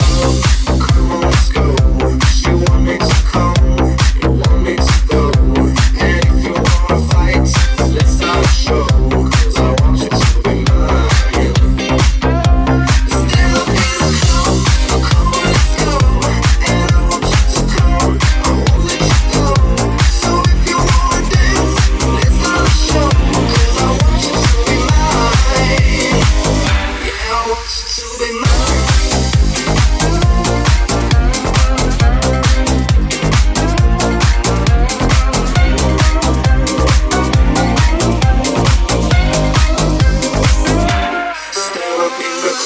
hit - nuove proposte - remix
Genere: pop, club, deep, remix